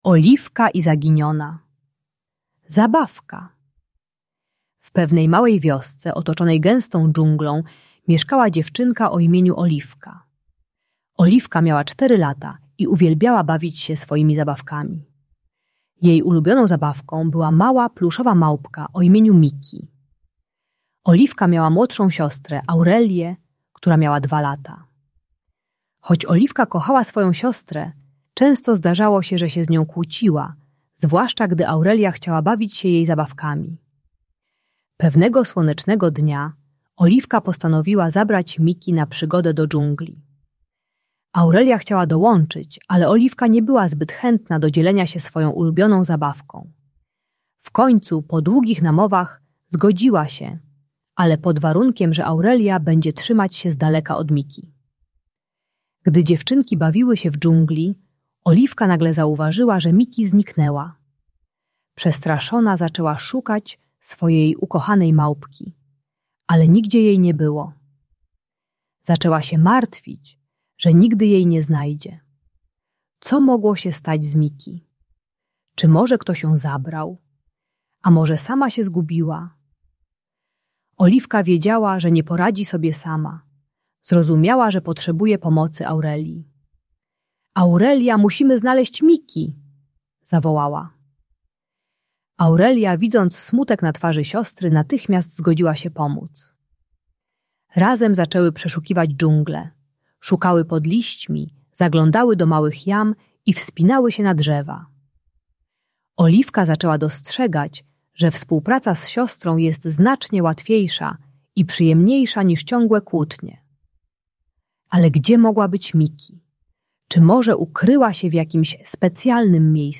Ta audiobajka o kłótniach między rodzeństwem uczy współpracy i pokazuje, że dzielenie się zabawkami przynosi radość.